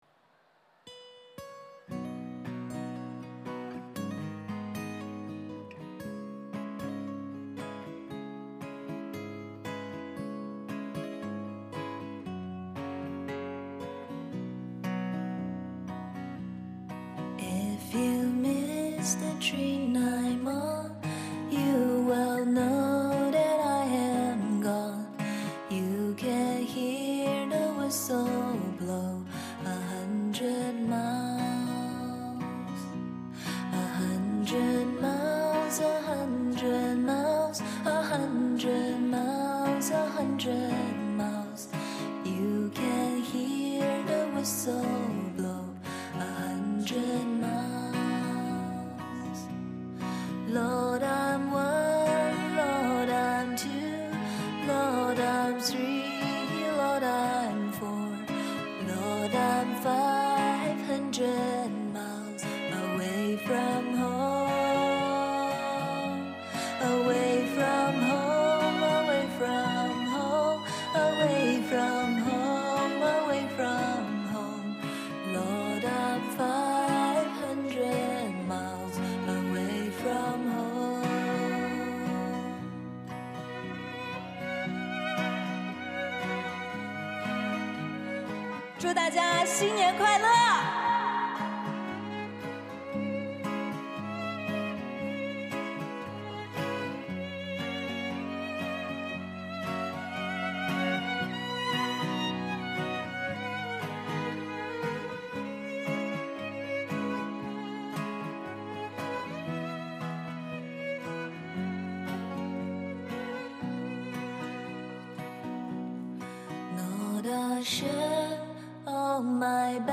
重复的旋律，表达了一个在外漂泊打拼的游子向亲人诉说着不舍与留恋之情。
这首民谣旋律优美，自然清新，又富有哲理。
这是一首饱含浓浓乡愁的曲子，让每一个背井离乡的游子沉浸在歌的意境中无法自拔。